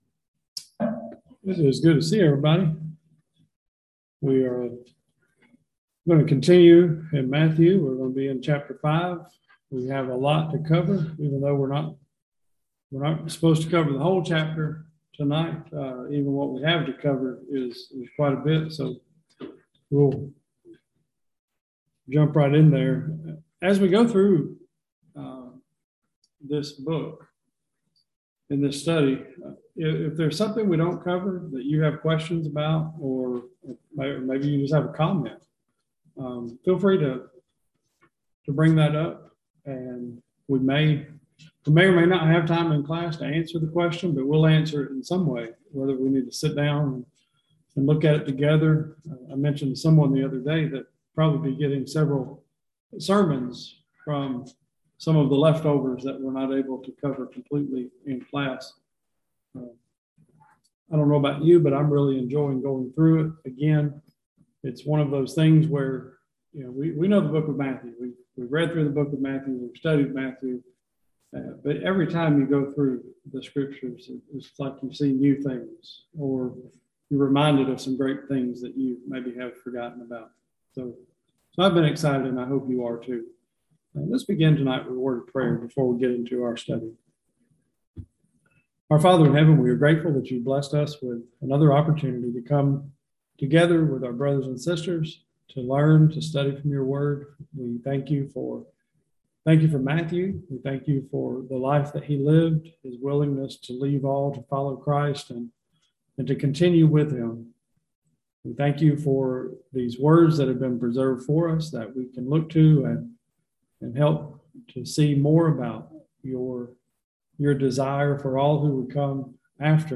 Service Type: Bible Classes